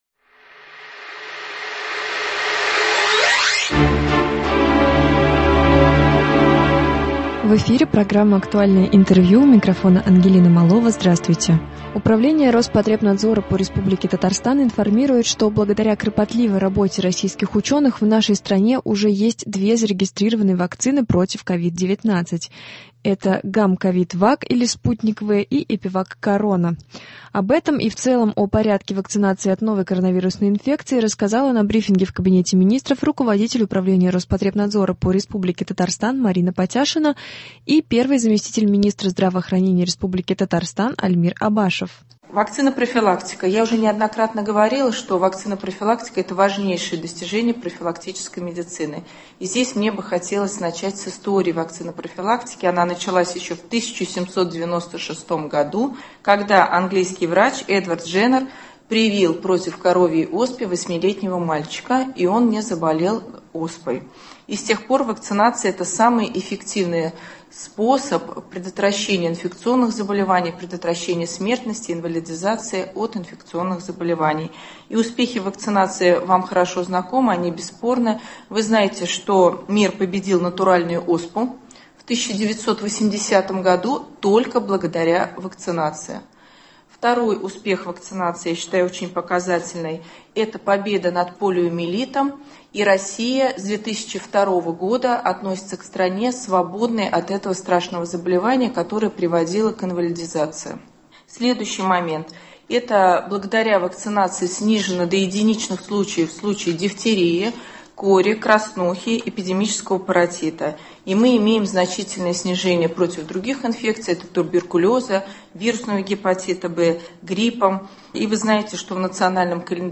Актуальное интервью (13.01.21)